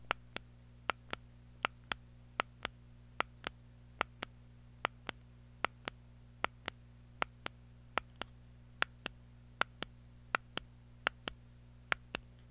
Next is the New Best CX24 Gold fire button being pressed and released.
Best Gold CX24 Fire button WAV sound byte
On the Best Gold fire button, you will hear a louder and sharper 1st click (as the dome contact is depressed) sound and a very quick second softer click sound (Gold dome contact being released with the tactual feed back click / kick back feature).
Best CX24 Fire button sound.wav